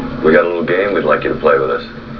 THE VOICE OF LANCE HENRIKSEN